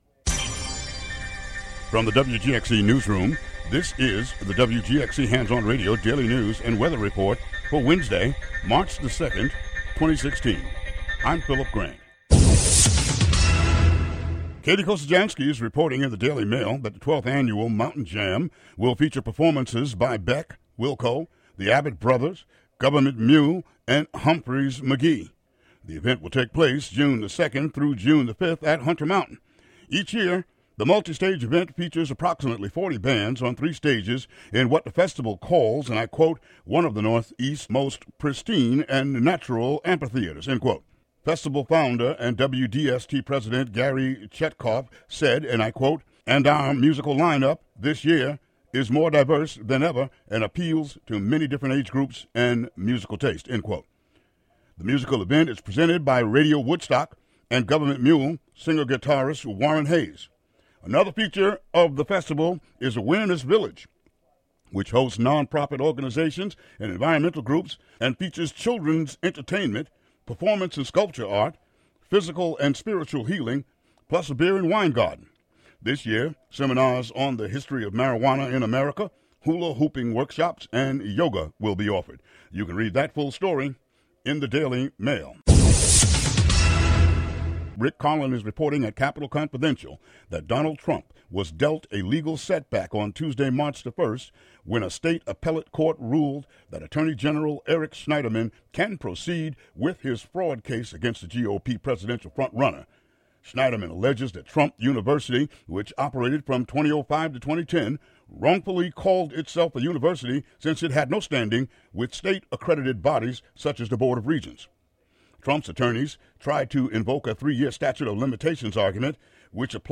Local headlines and weather for Wed., Mar. 2.